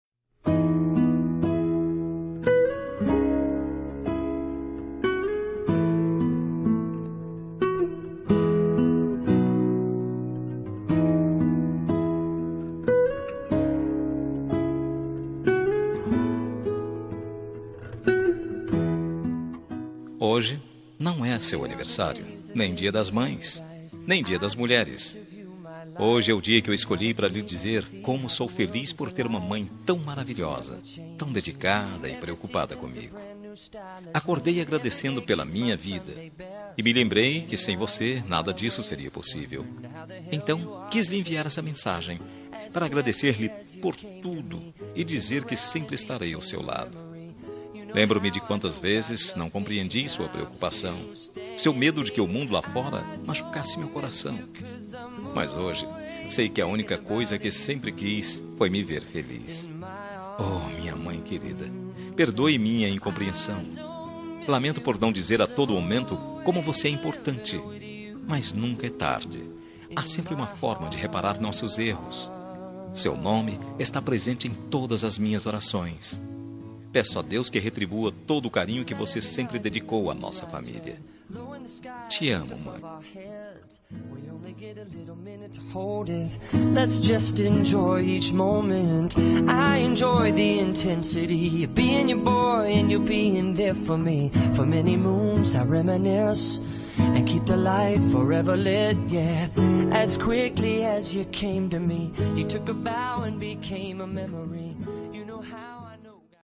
Homenagem para Mãe – Voz Masculina – Cód: 8144